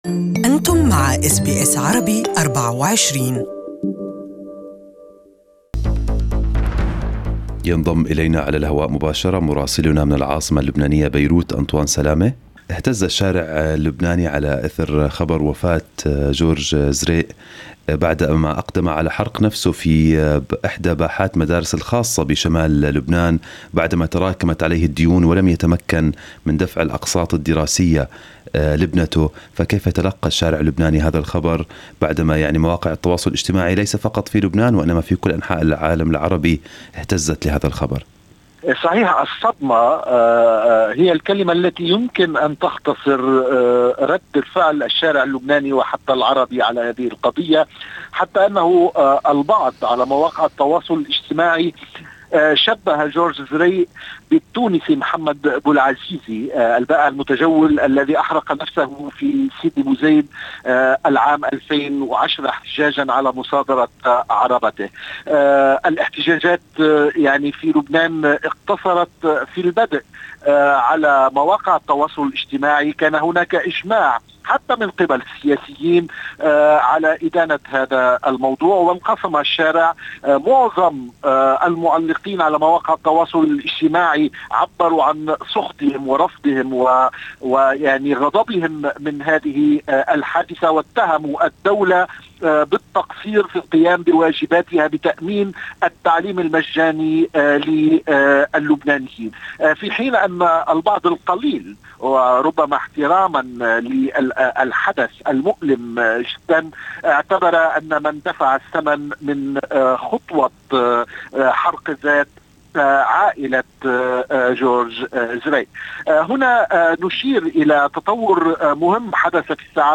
Our correspondent in Beirut has the details
Listen to the full report from Beirut in Arabic above